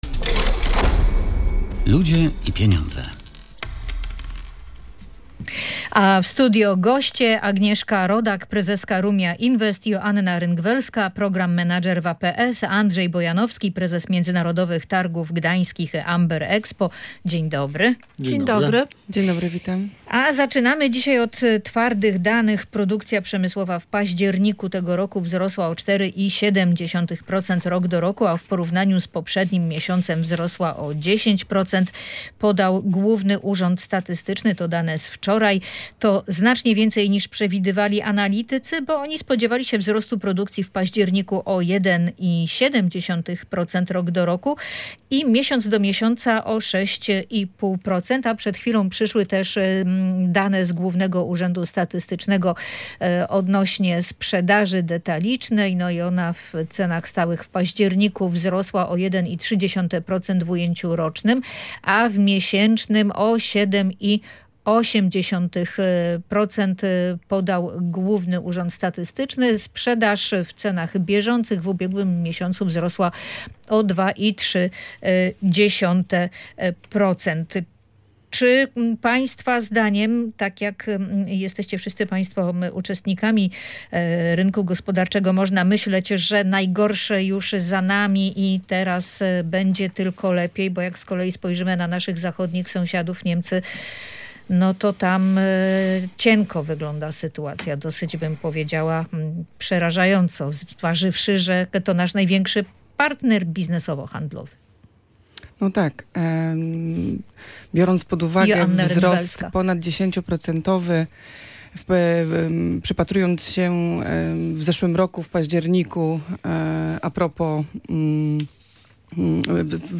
O okazjach i przecenach w Black Friday, ale też o zagrożeniach, jakie czyhają na „łowców okazji” rozmawiali w audycji „Ludzie i Pieniądze”